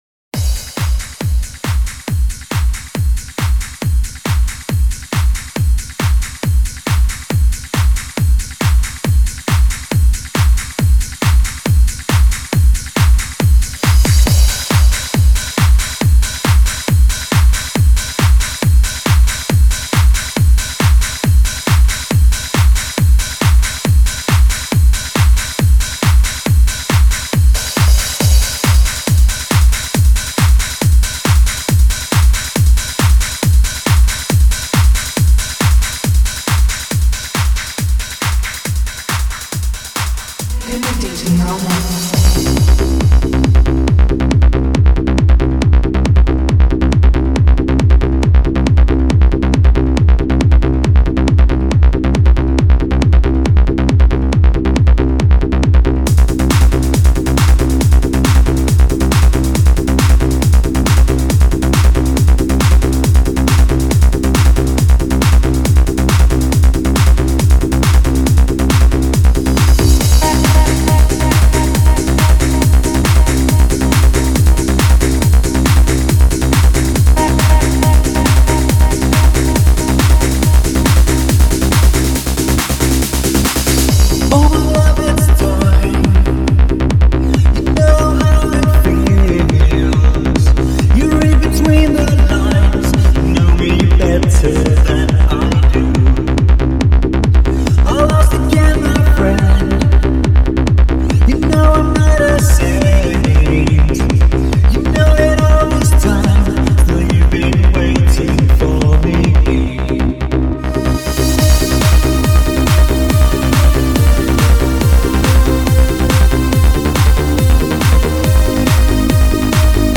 mix/mash
it begged for mixing and mashing to make a haunting melody